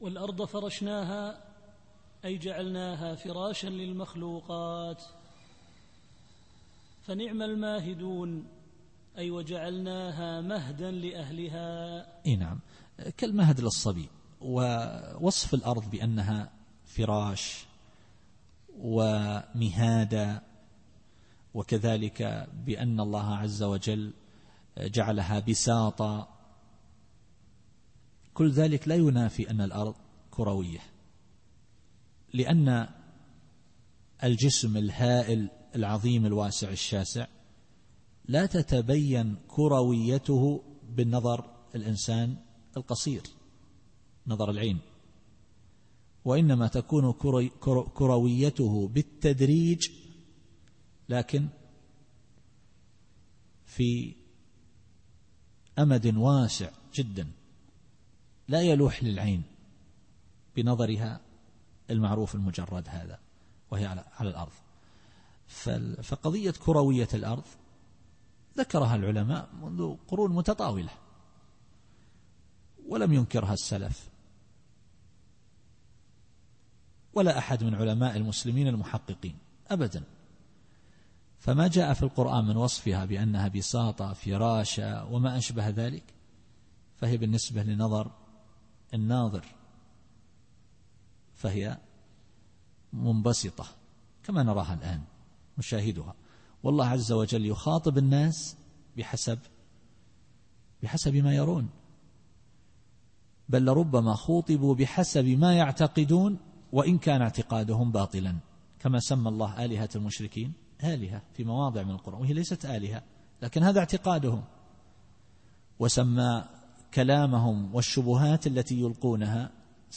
التفسير الصوتي [الذاريات / 48]